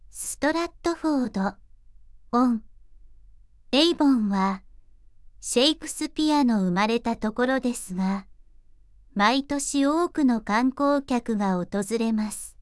voicevox-voice-corpus / ita-corpus /九州そら_ノーマル /EMOTION100_006.wav